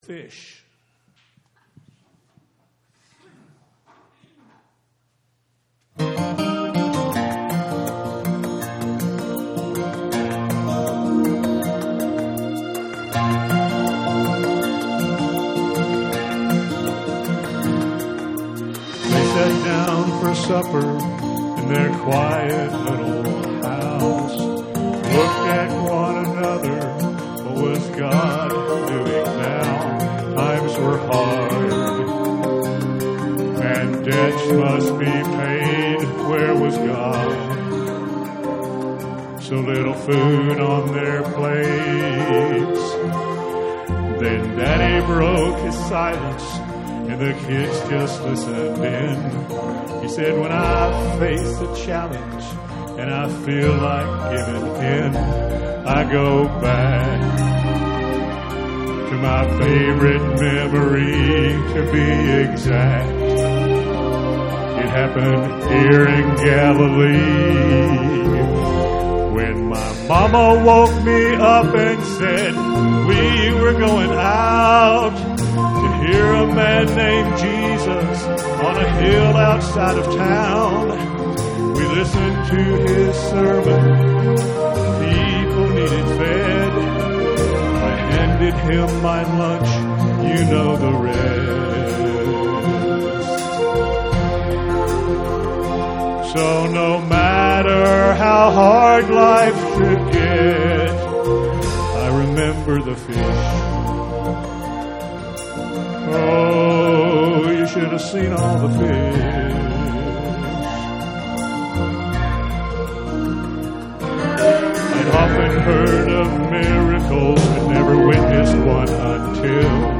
Special Music - Calvary Baptist Church